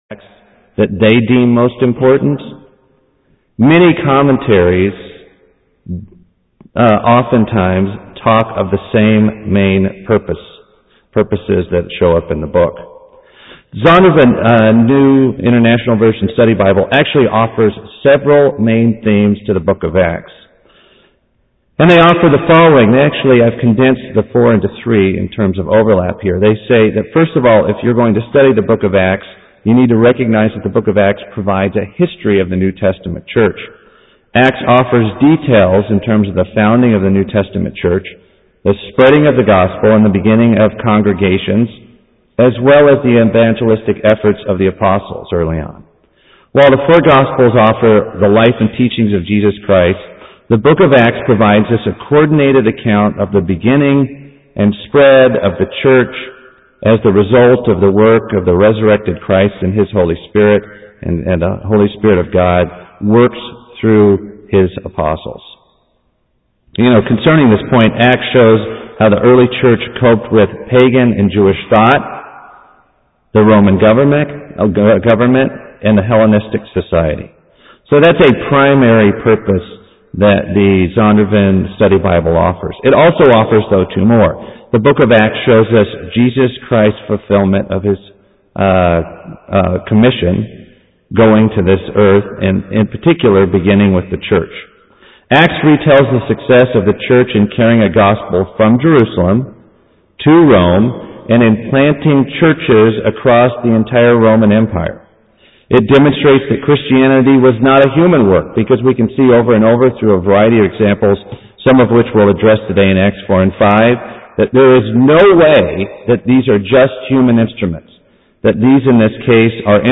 Given in Atlanta, GA Buford, GA
Acts 5:40-41 - rejoicing in trials UCG Sermon Studying the bible?